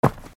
StoneFootsteps_1.ogg